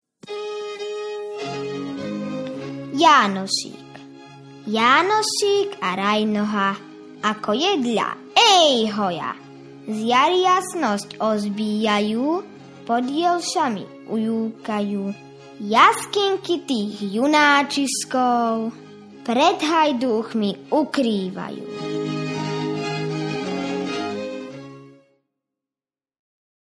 • Básne na CD recitujú interpreti rôznych vekových kategórií – deti vo veku 6 až 9 rokov, pani učiteľky, školené hlasy profesionálnych umelcov, až po 82-ročnú pani učiteľku.
• Básne sprevádzajú krásne hudobné motívy – najmä fujarové sklady, rôzne ľudové nástroje         – fujara, gajdy, píšťalky, harmonika, cimbal, husle, ale i hudobné motívy svetovej hudobnej tvorby.